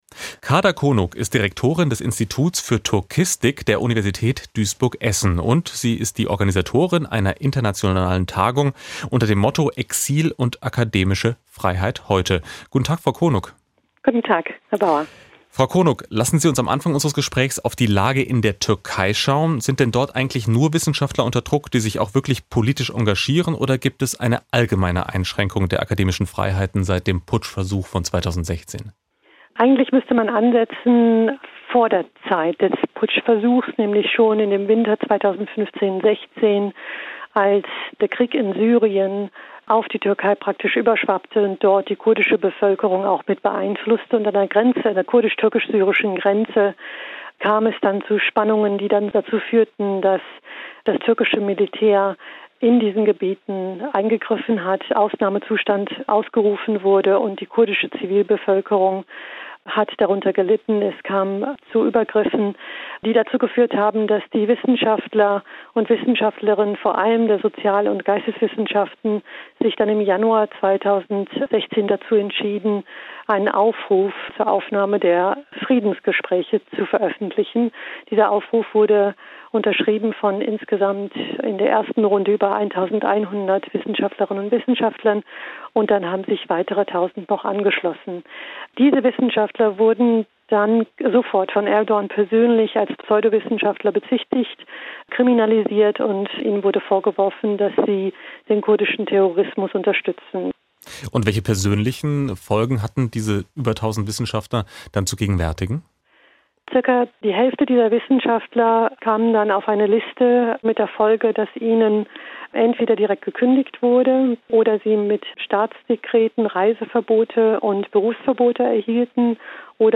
SWR2 Politisches Interview   /     Konferenz über wissenschaftliches Arbeiten im Exil: "Academy in Exile"